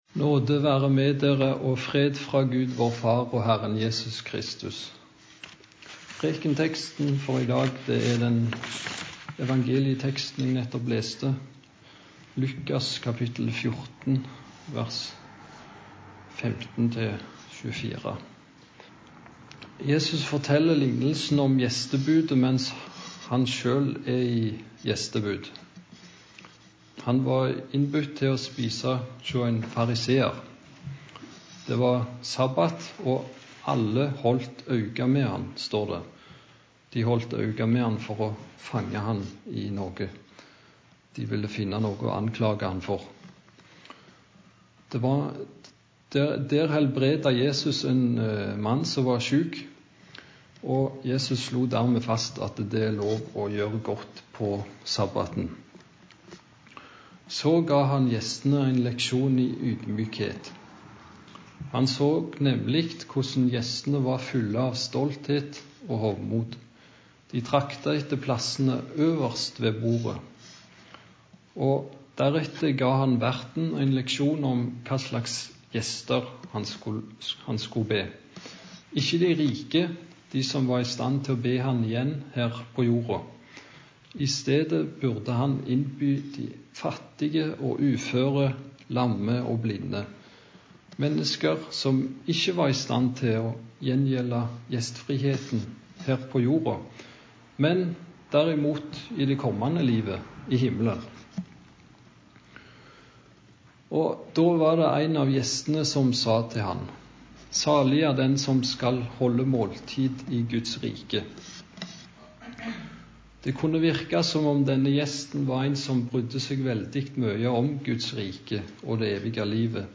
Preken på 2. søndag etter Treenighetsdag